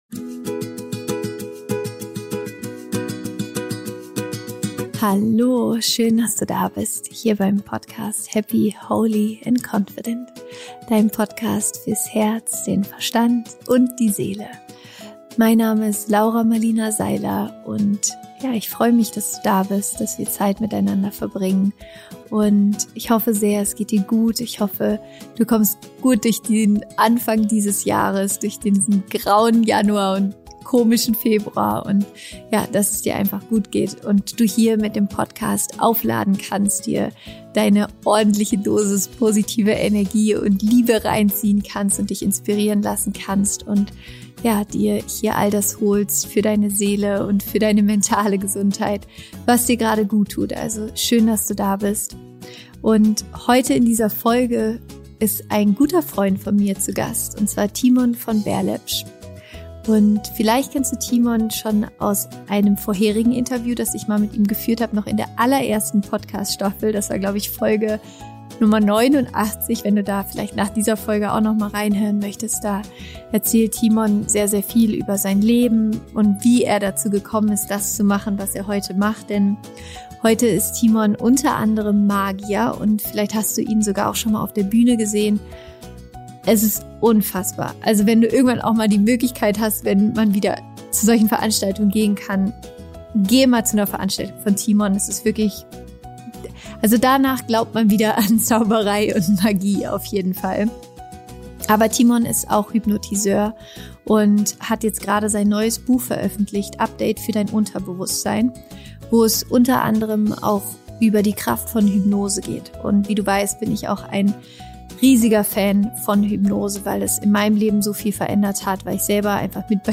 Im Interview sprechen wir darüber, wie du es schaffst, negative Gedanken, Blockaden, ungewollte Verhaltensmuster und Gewohnheiten zu transformieren. Thimon macht mit uns eine kleine Hypnose-Übung zum Mitmachen, um zu zeigen, wie stark unsere Gedanken unsere körperlichen Reaktionen beeinflussen können.